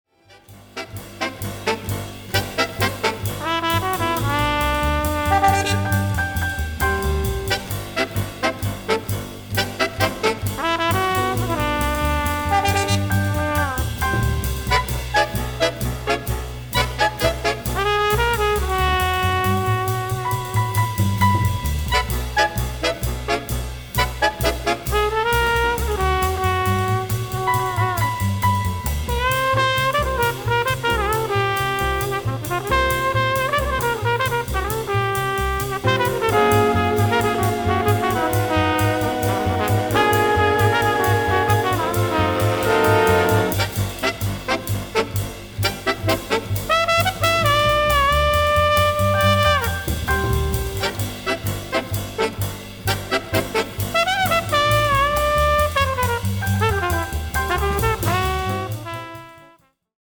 Just written for Flugelhorn and reeds